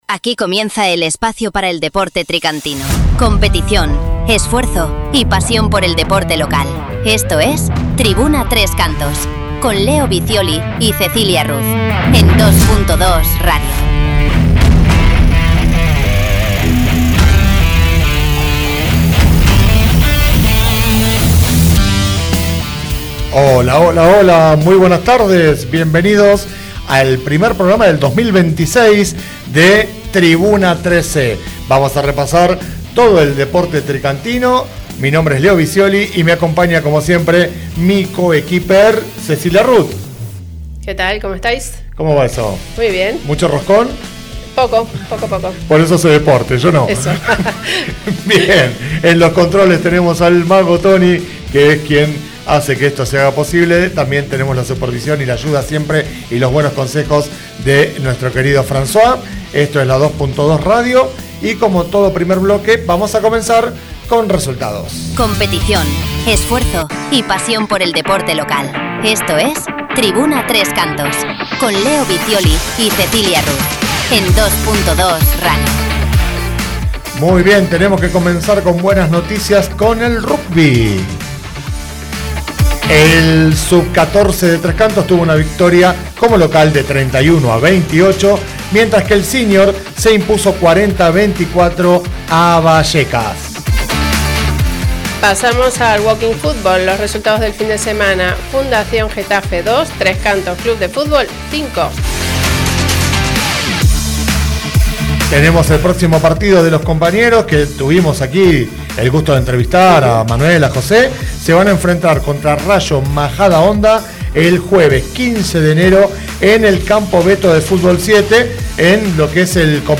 Comienza el programa con las noticias de la actividad del fin de semana mencionando los resultados de las distintas competiciones de los deportistas y equipos tricantinos En el segundo bloque, la entrevista